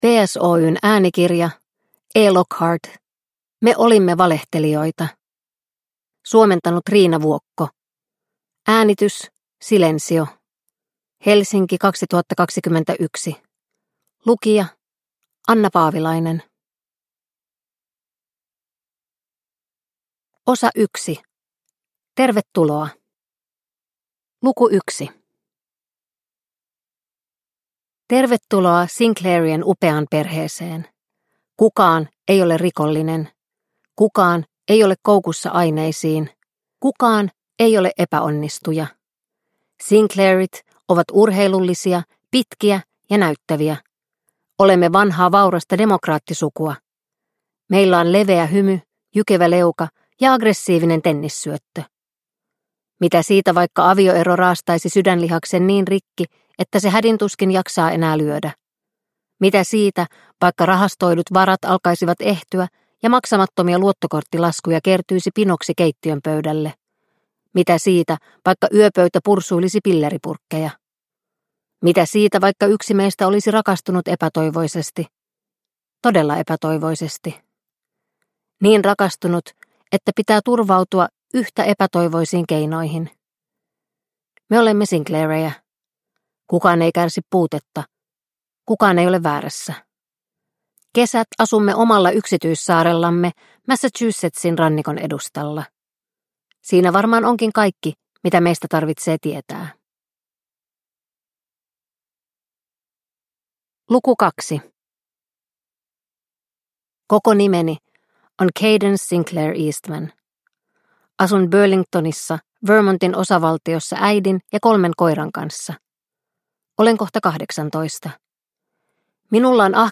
Me olimme valehtelijoita – Ljudbok – Laddas ner